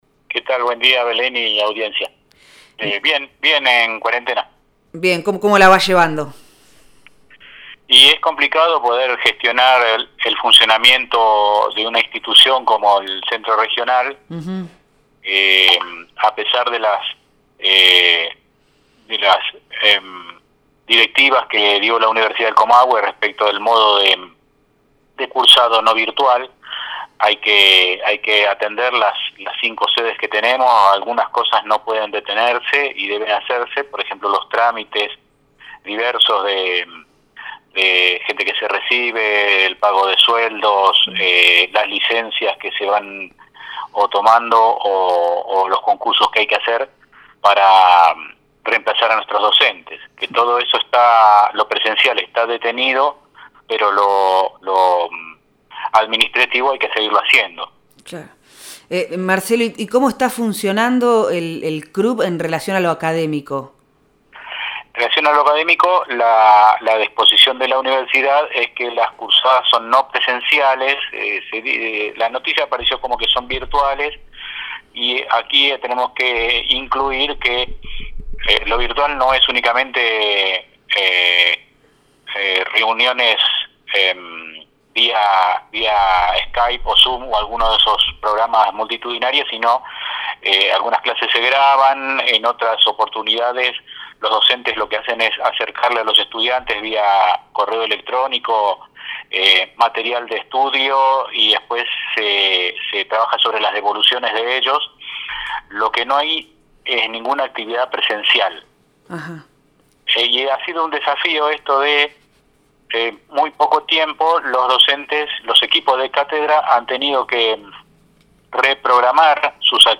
Proyecto Erre Radio , de lunes a viernes de 8 a 10 am por FM Vox Populi, 100.1; y por FM Del Barrio, 98.1